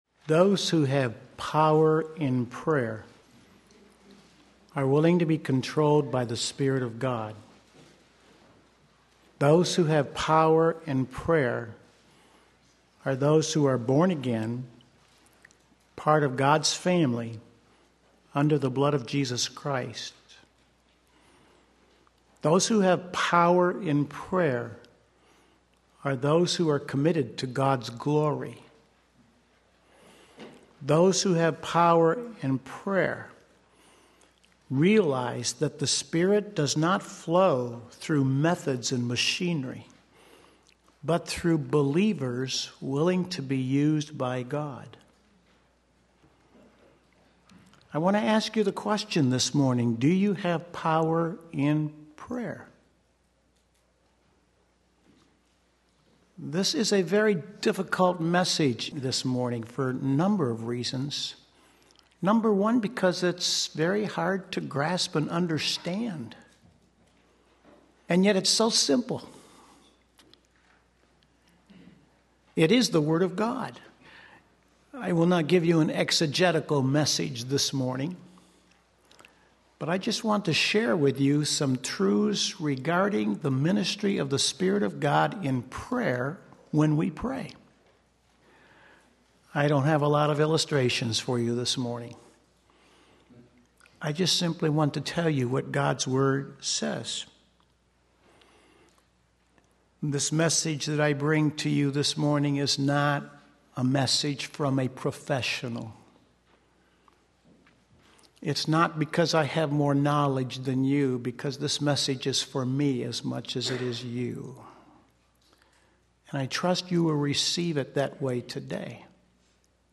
Sermon Link
Praying in the Spirit Romans 8:14-29 Sunday Morning Service